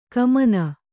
♪ kamana